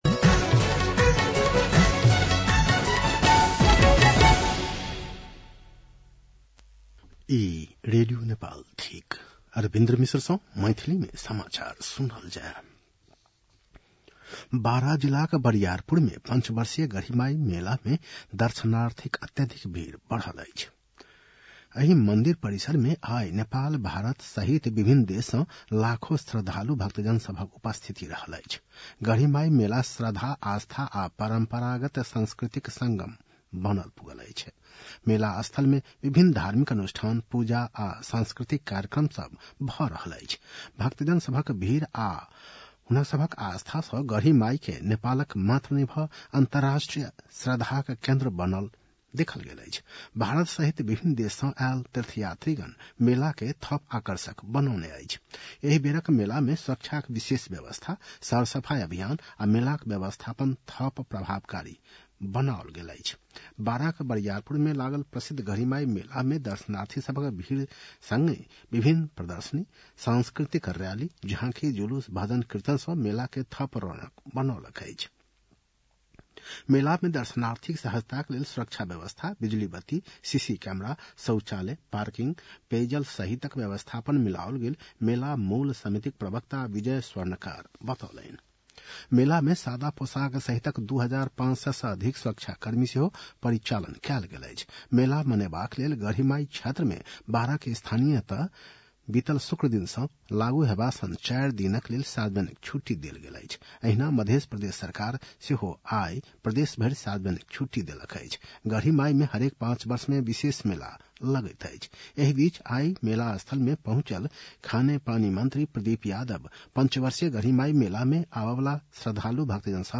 मैथिली भाषामा समाचार : २४ मंसिर , २०८१